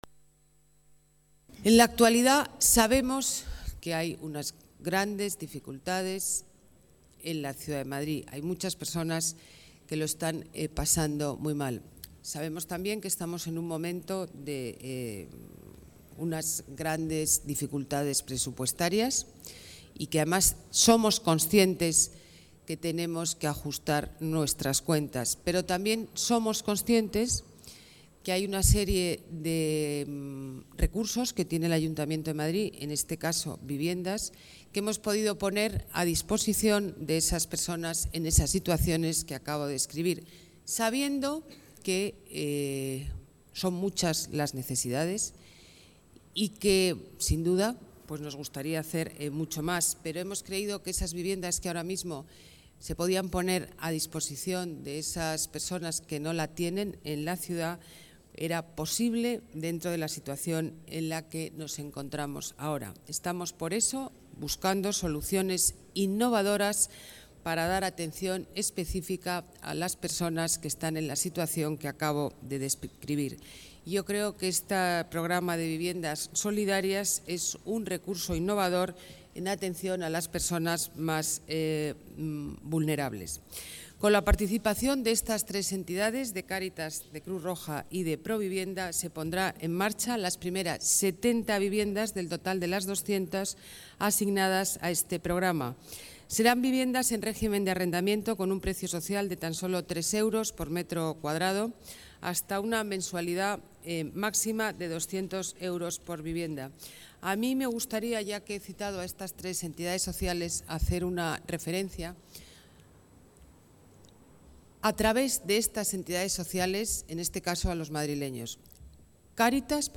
Nueva ventana:Declaraciones alcaldesa Ana Botella: 70 viviendas por la cohesión social